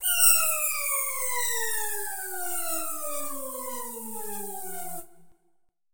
SouthSide Trap Transition (38).wav